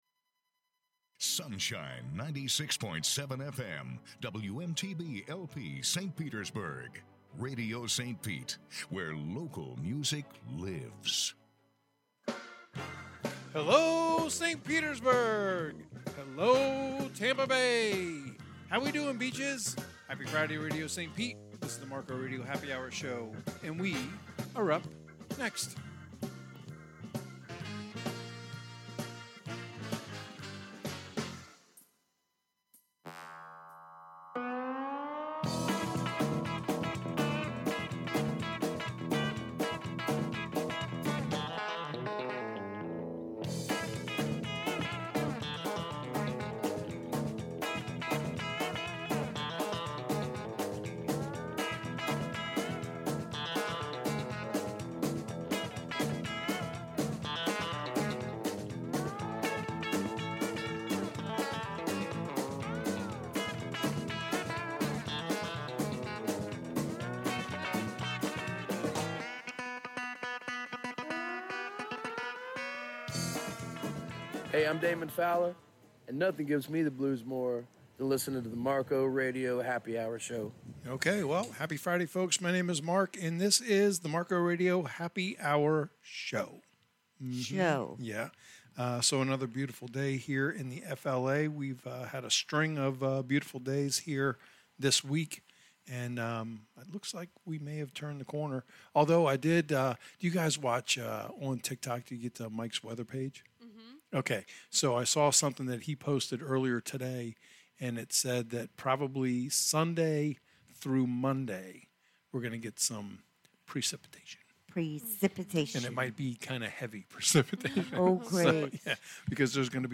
Live on 96.7 FM Fridays 6-8pm ET